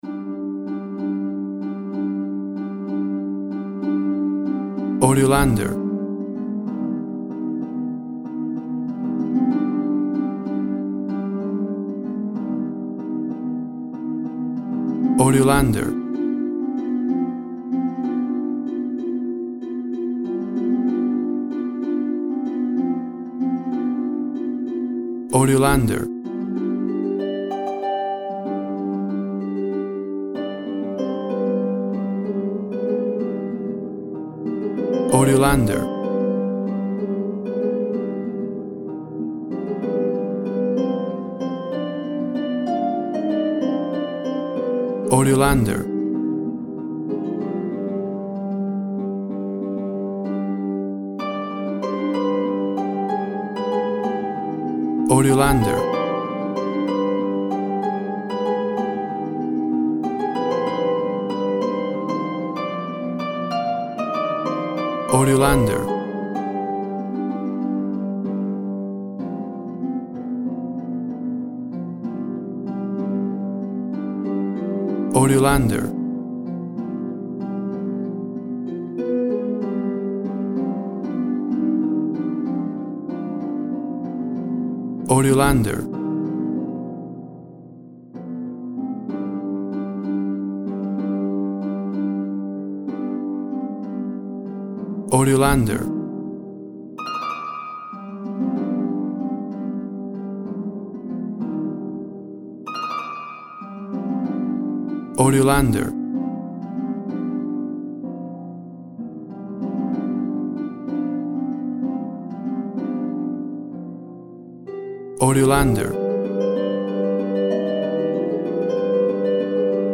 WAV Sample Rate 16-Bit Stereo, 44.1 kHz
Tempo (BPM) 185